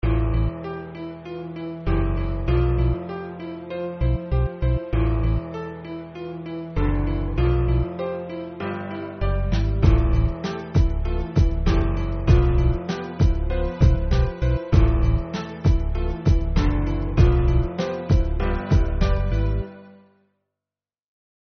Минусовки (Hip-Hop)
Агрессивные:
3. (инструментальный); темп (98); продолжительность (3:35)